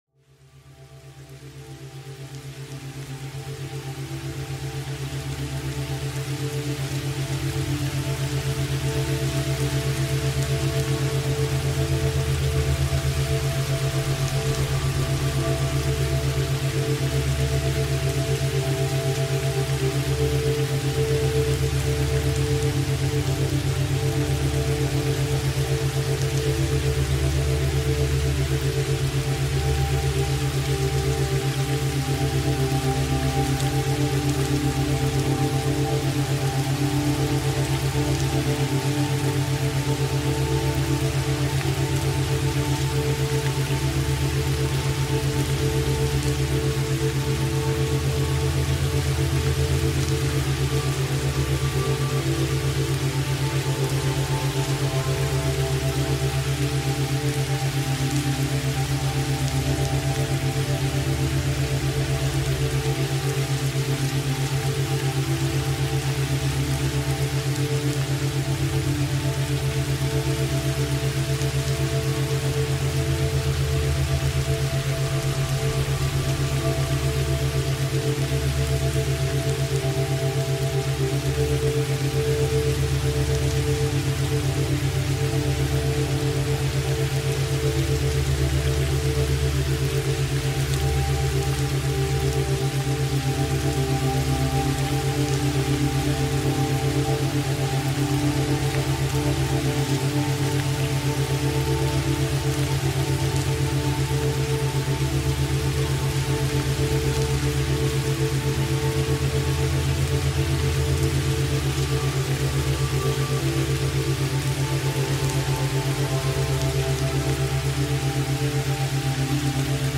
雨が静かに窓を叩くように、雨の音が思考を包み込む。リラックス音楽が、緊張をやわらげ、体の隅々まで安らぎを届ける。
勉強BGM